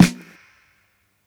click_snr.wav